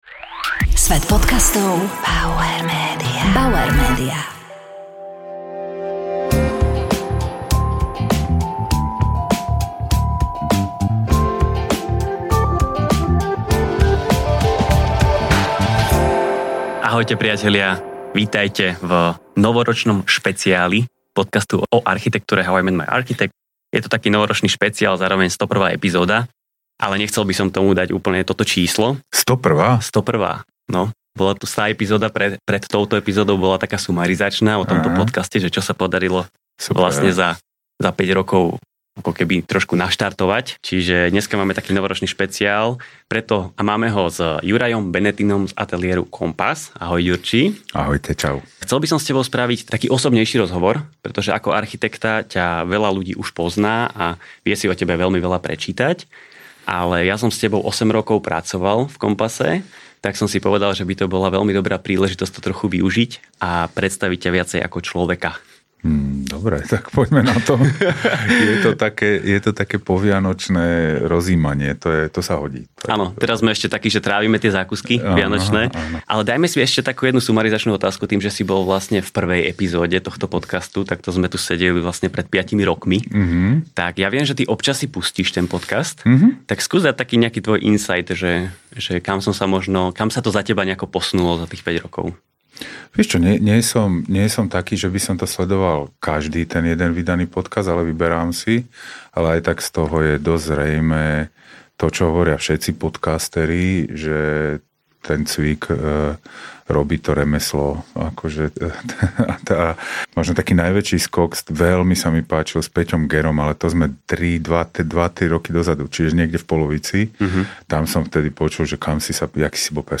Juraj Benetin z ateliéru COMPASS architekti v netradične osobnom rozhovore . Juraj je dnes úspešný architekt a spevák v skupine Korben Dallas, čo však považuje za úspech sám pred sebou? Aká aktivita mu pomáha dobiť baterky, keď je toho priveľa a kedy mal vážnu pracovnú krízu? Aká je jeho súčasná rola v dlhodobých urbanistických projektoch a ako vedome vplýva na spolupracovníkov v ateliéri?